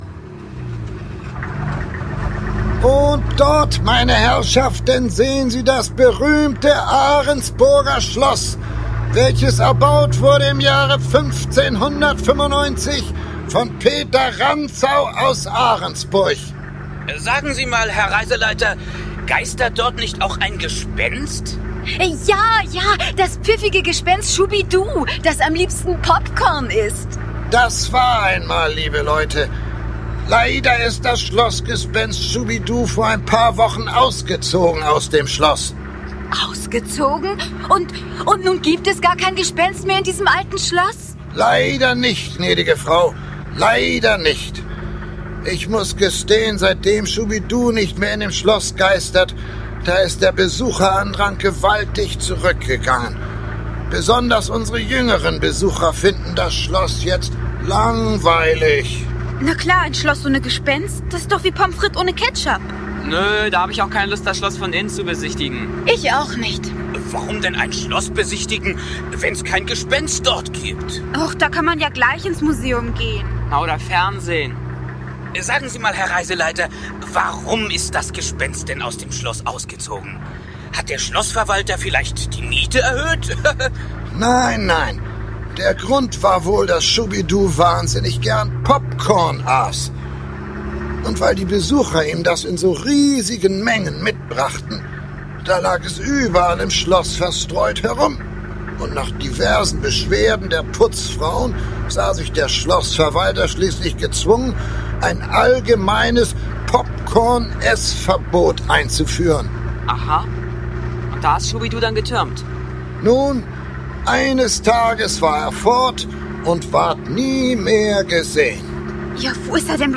Ravensburger Folge 2: Schubiduund ..uh - und sein Schatz ✔ tiptoi® Hörbuch ab 4 Jahren ✔ Jetzt online herunterladen!